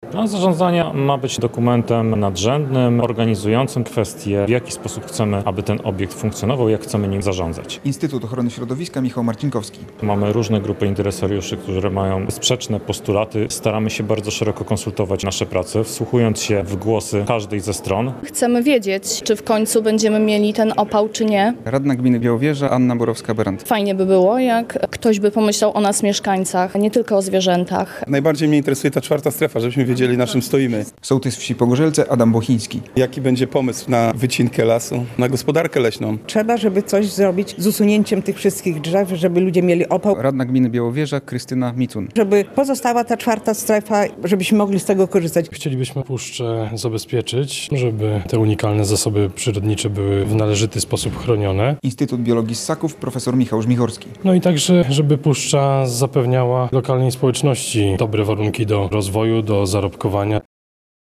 O przyszłości Puszczy Białowieskiej dyskutują w Białowieży naukowcy i samorządowcy